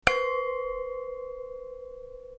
wine-glass.mp3